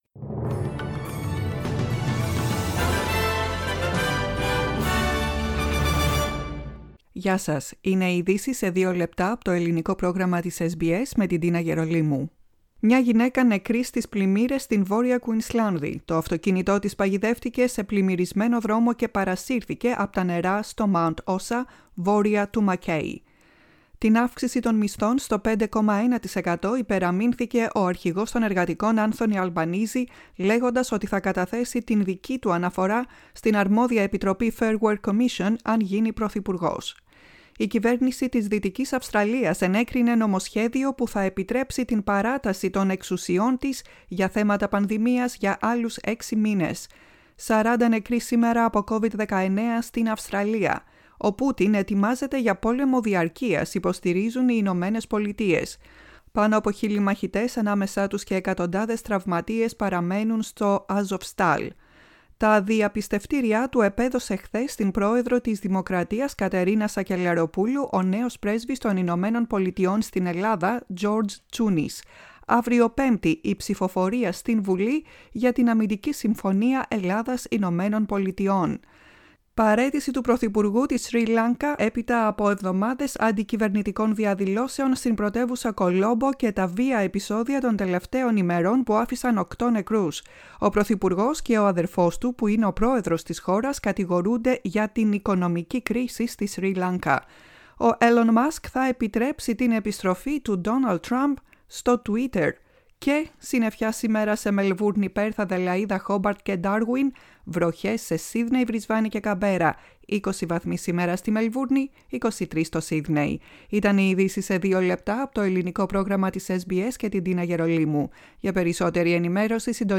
Greek News Flash.